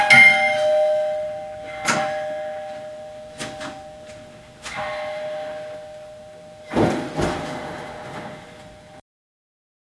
Звонят в дверь несколько раз